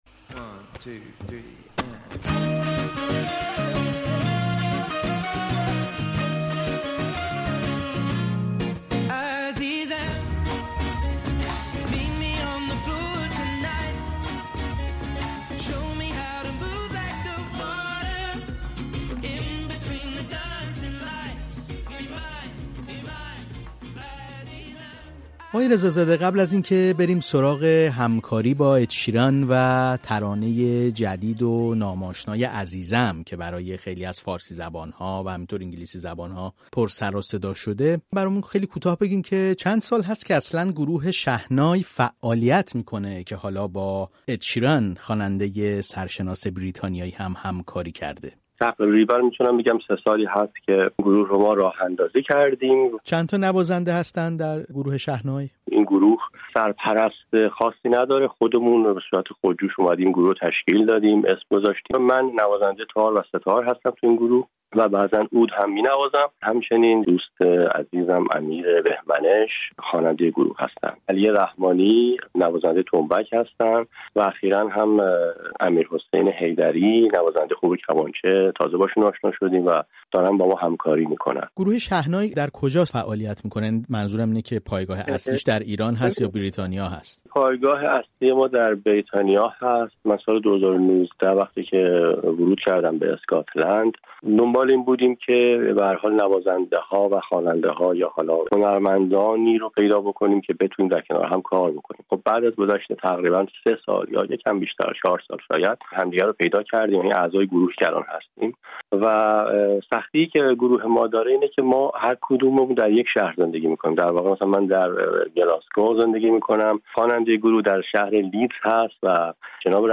گفت‌وگو کرده‌ایم که می‌شنوید.